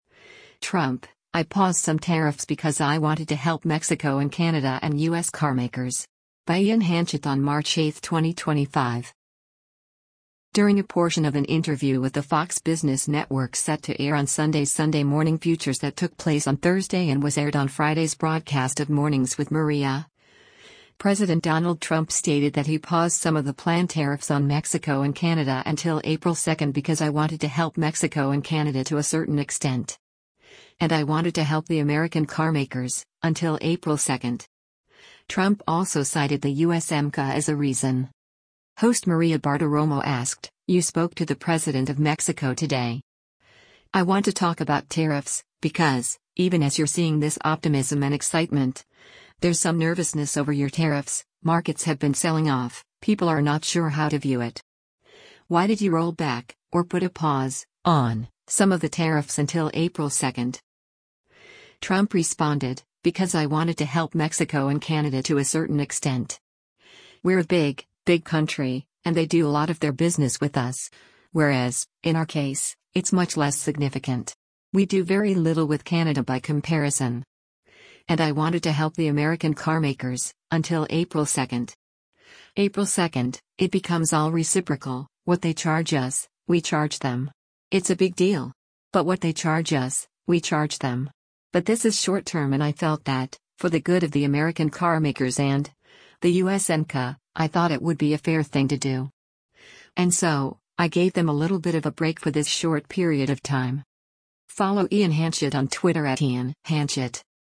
During a portion of an interview with the Fox Business Network set to air on Sunday’s “Sunday Morning Futures” that took place on Thursday and was aired on Friday’s broadcast of “Mornings with Maria,” President Donald Trump stated that he paused some of the planned tariffs on Mexico and Canada until April 2 because “I wanted to help Mexico and Canada to a certain extent.”